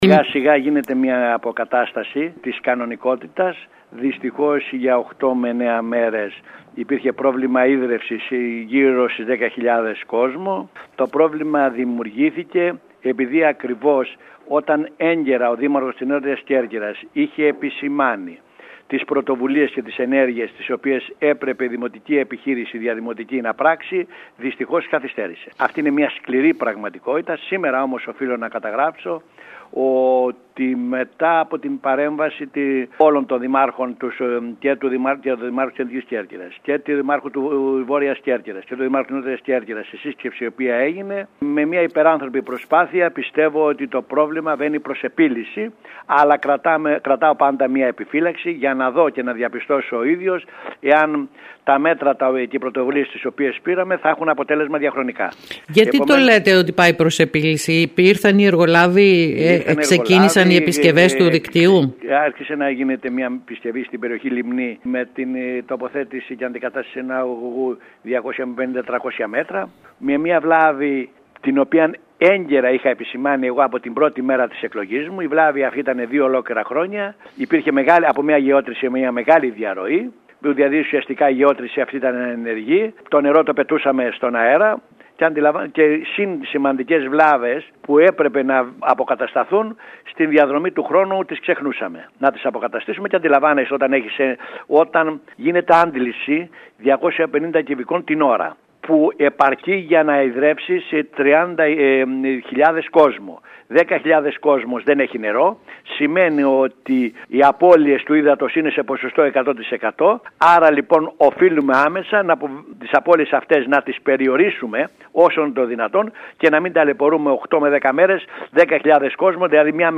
Οι παρεμβάσεις αφορούσαν σοβαρές βλάβες, μάλιστα, όπως δήλωσε ο Κώστας Λέσσης μιλώντας σήμερα στην ΕΡΤ Κέρκυρας, η μία από αυτές τις βλάβες προκαλούσε τόσο μεγάλη διαρροή που ακύρωνε μια ολόκληρη γεώτρηση.